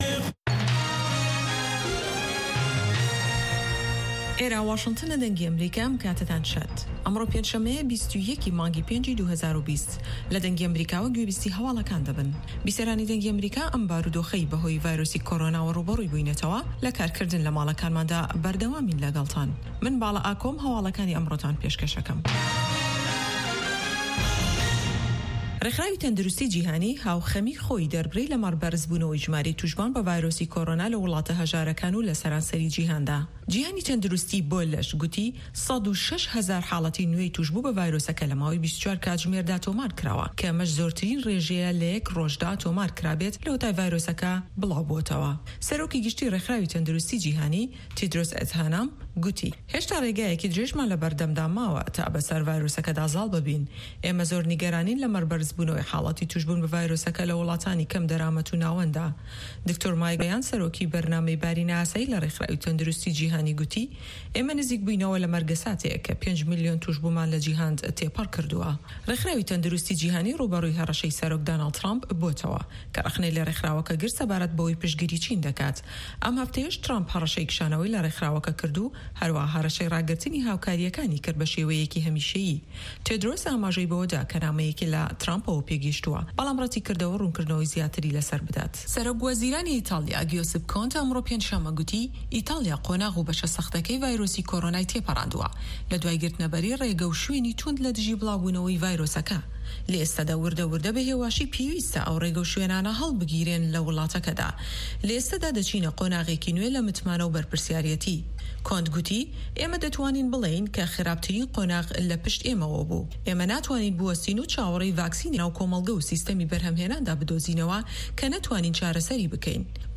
سه‌ره‌تای به‌رنامه‌ و چه‌ند هه‌واڵێـک